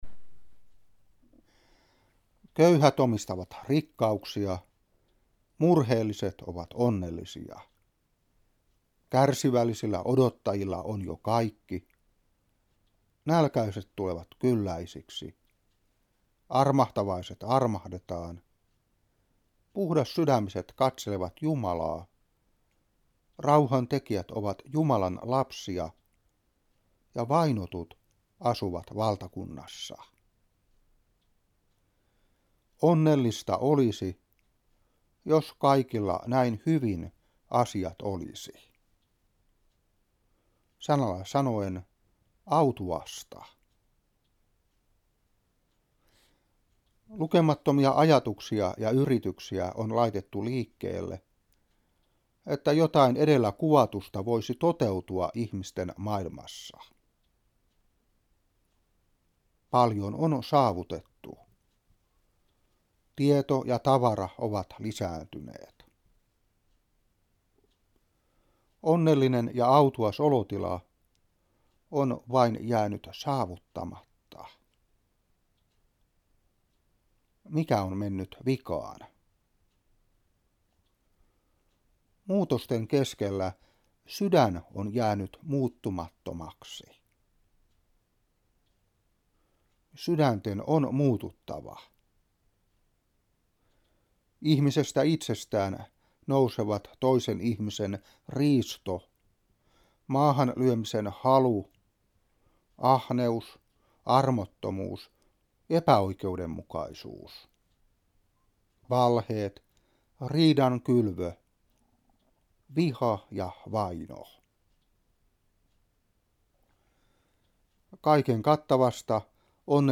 Saarna 2003-11.